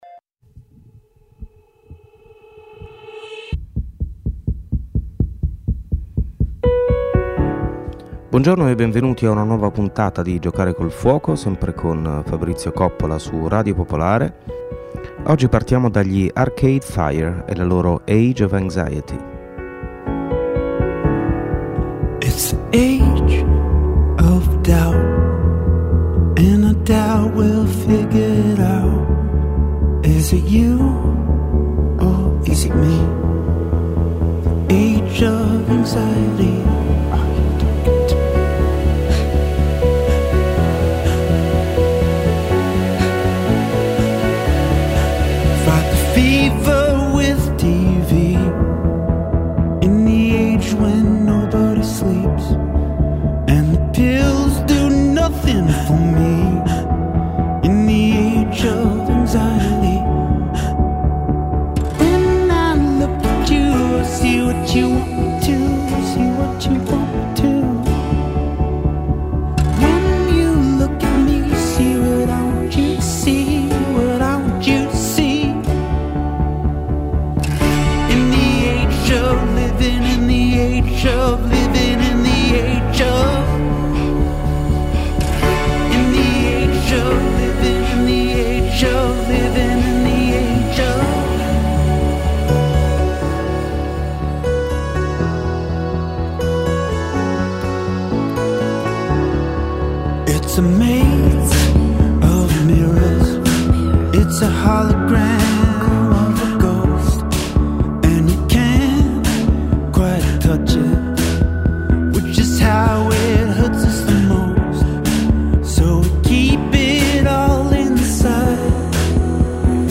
Letture